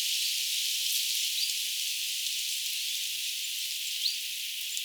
tiltalttilintu joka äänteli tulit.
tuvit-tiltaltti, 1
tuvit-tiltaltti_mika_laji_tama_olikaan.mp3